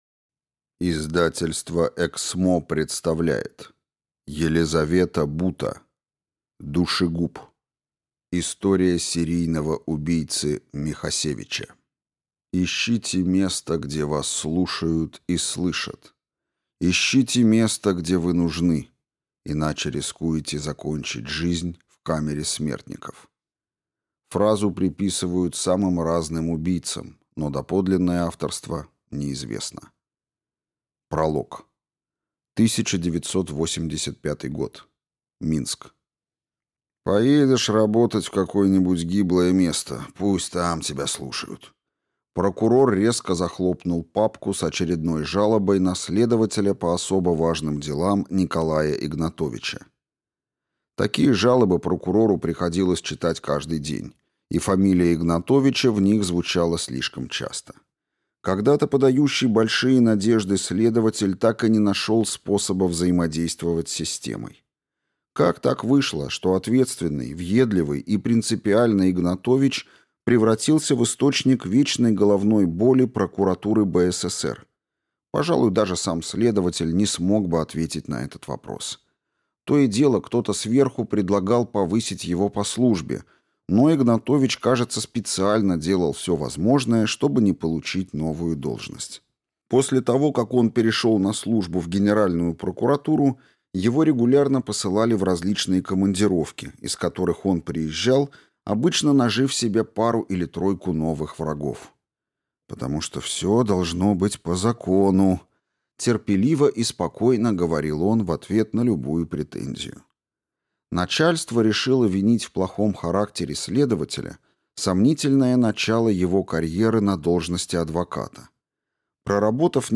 Аудиокнига Душегуб. История серийного убийцы Михасевича | Библиотека аудиокниг